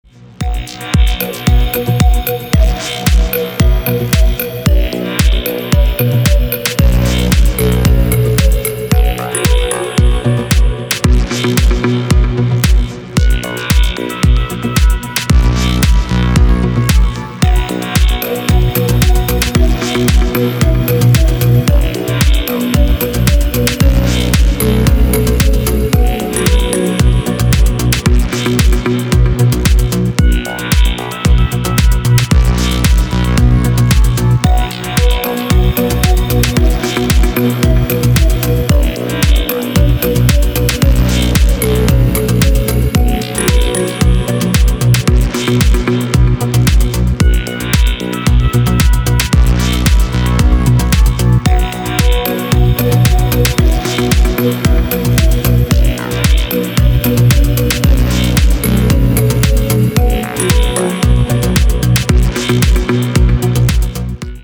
• Песня: Рингтон, нарезка
• Категория: Красивые мелодии и рингтоны
теги: красивый рингтон